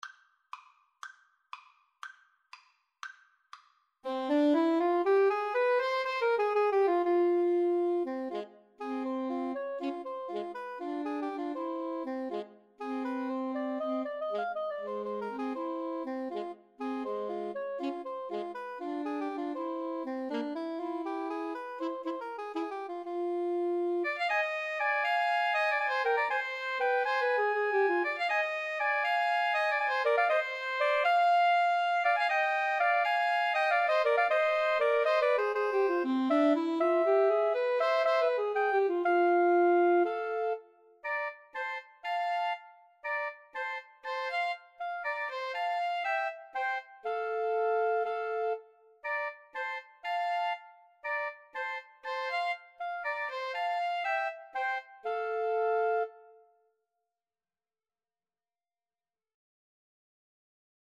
Soprano SaxophoneAlto SaxophoneTenor Saxophone
C minor (Sounding Pitch) (View more C minor Music for Woodwind Trio )
Allegro (View more music marked Allegro)
2/4 (View more 2/4 Music)